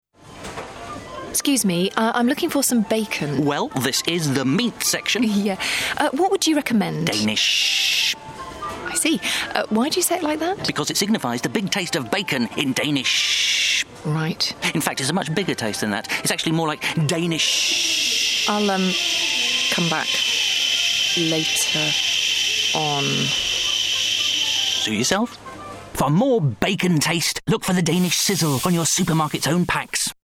It cleverly uses the product’s name to emulate the unmistakable sizzle of bacon, instantly conjuring up dreamy images (and perhaps even the delightful aroma) of bacon sizzling in the pan.